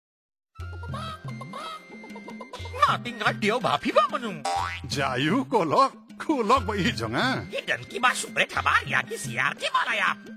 This is fourth in the series of five Radio PSA and address backyard farmers and their families. It also uses a performer and a rooster puppet as a creative medium to alert families to poultry diseases and instill safe poultry behaviours.
Radio PSA